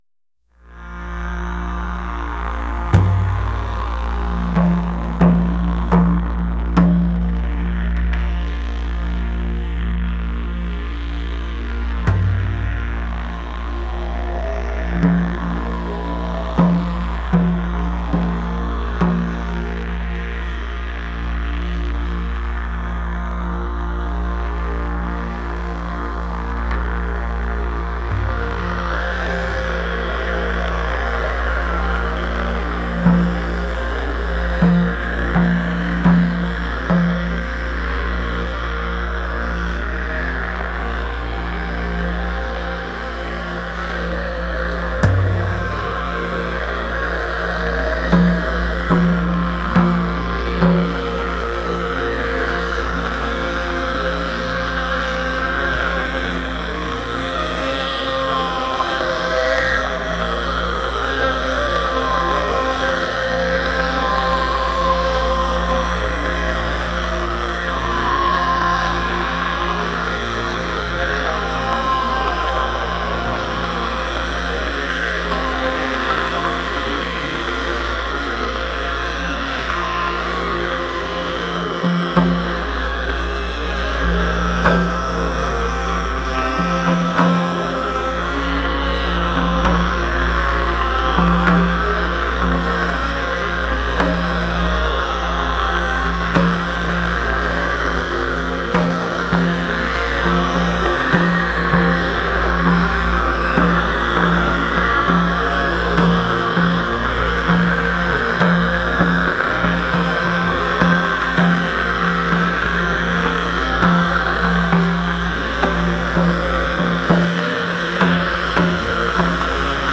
Exhausted, the crew (try to) settle down for the night, and the Shaman leads a chorus of droning music that does little to help them sleep.